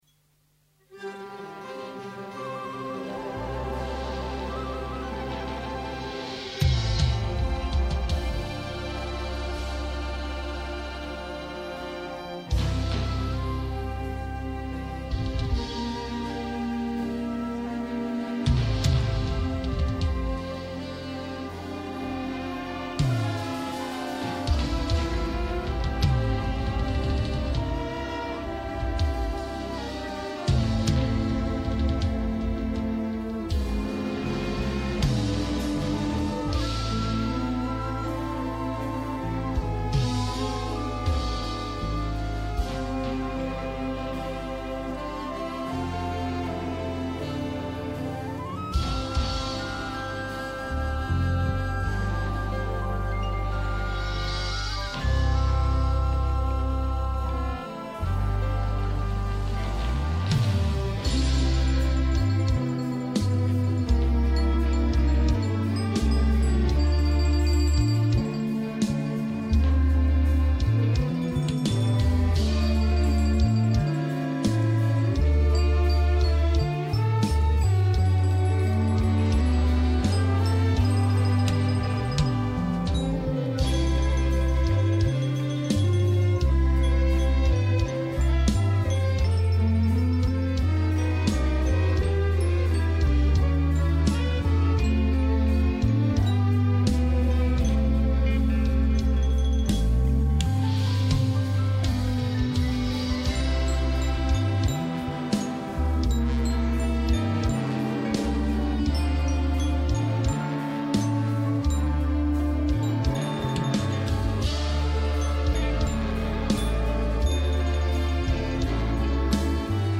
No Lyrics…